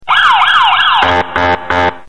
Категория: Разные звуки